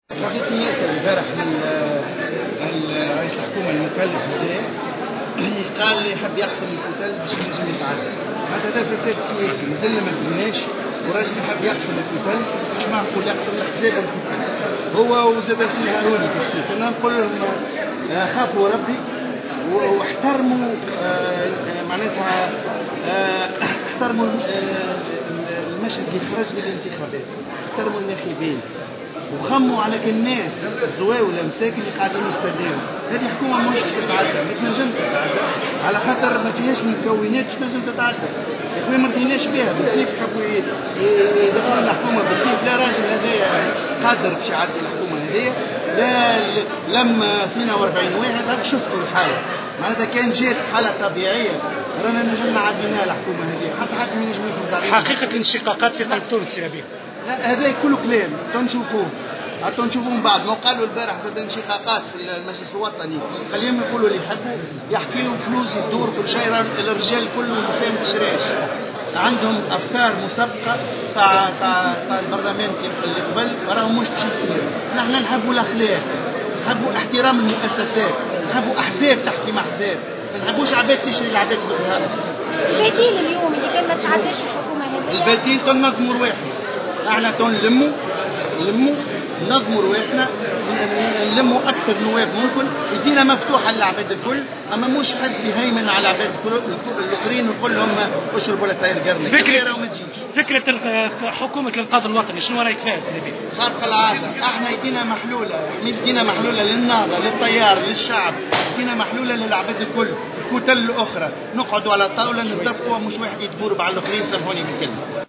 وشدد القروي، في تصريح لمراسل الجوهرة أف أم، على هامش الجلسة العامة بمجلس نواب الشعب المخصصة لمنح الثقة لحكومة الجملي، على تماسك كتلة حركة قلب تونس، مؤكدا أن التصويت سيثبت عكس ادعاءات رئيس الحكومة المكلف.